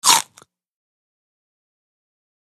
SnglShrtBiteCelery PE677901
DINING - KITCHENS & EATING CELERY: INT: Single short bite into celery.